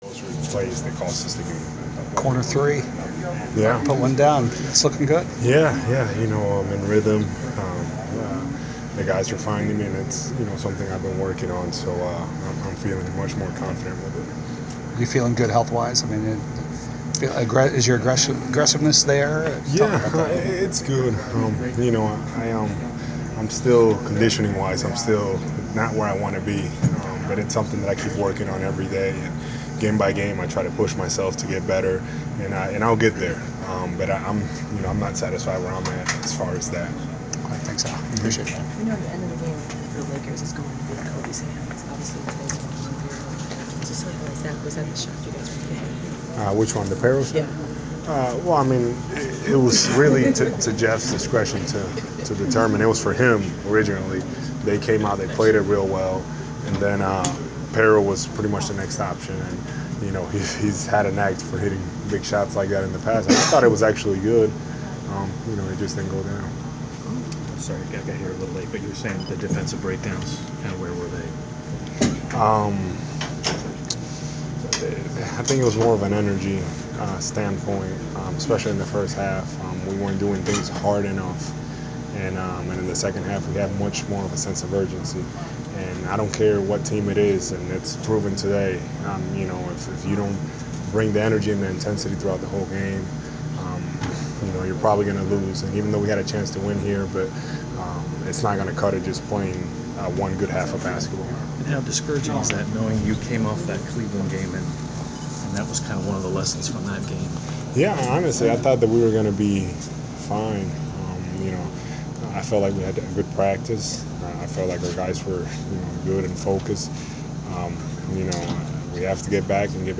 Inside the Inquirer: Postgame interview with Atlanta Hawk Al Horford 11/18/14
hawks-horford-post-lakers.wav